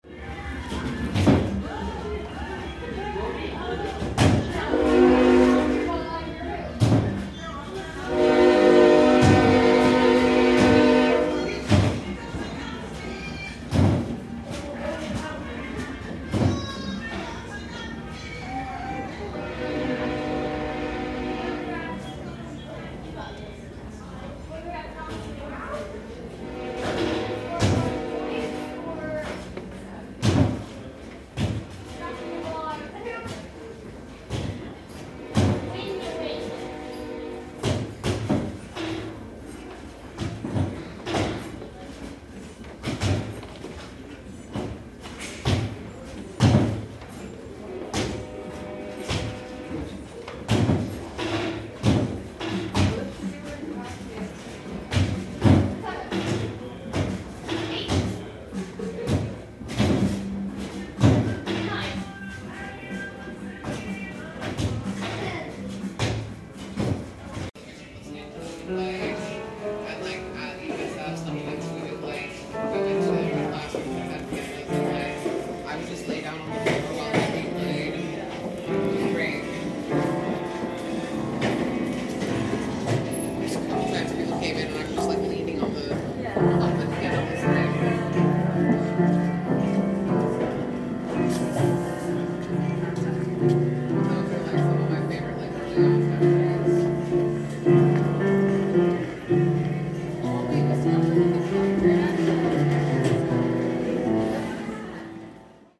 Field Recordings
Portland-Memory-Den-train-basketball-and-piano.mp3